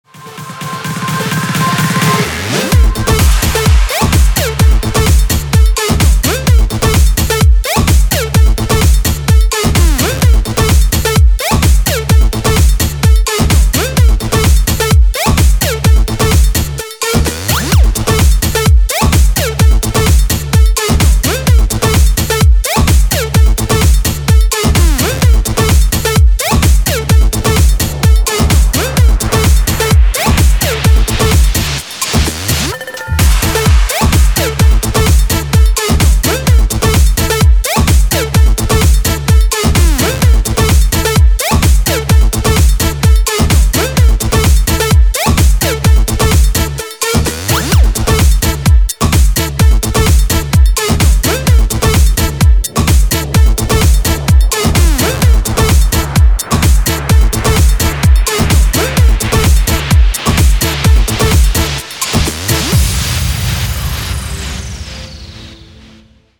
• Качество: 320, Stereo
house
бодренький ремикс